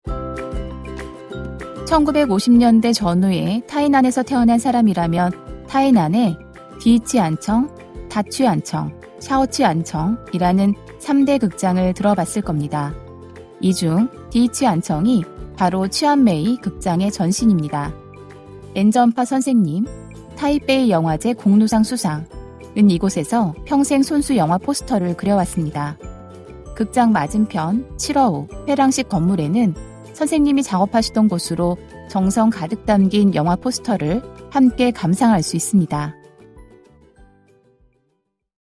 한국어 음성 안내